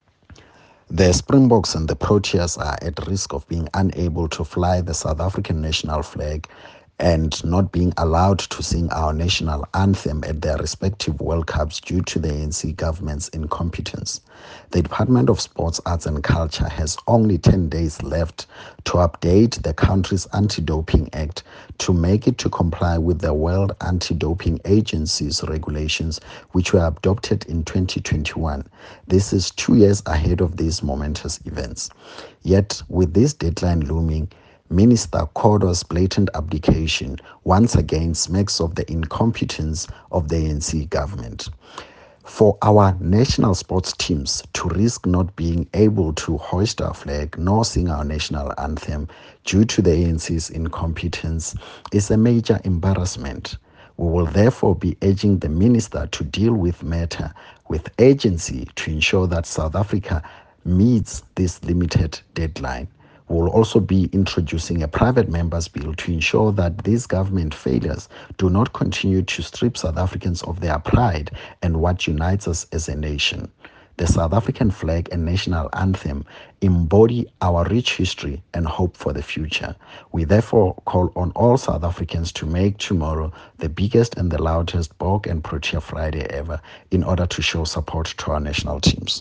soundbite by Solly Malatsi MP